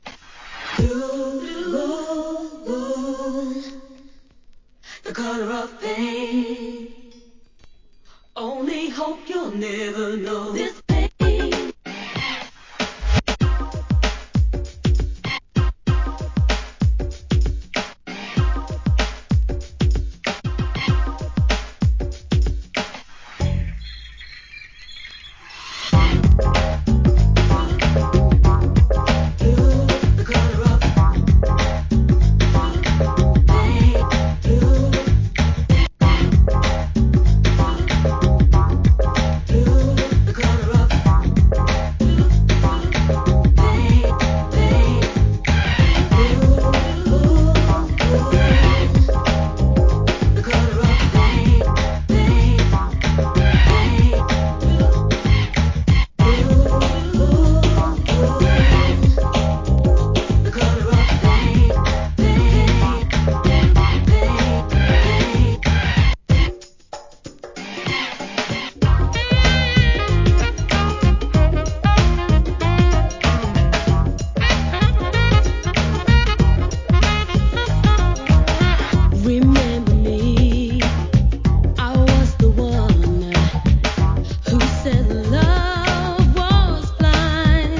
HIP HOP/R&B
程よいテンポの跳ねたグランドビートで絡むSAXも最高！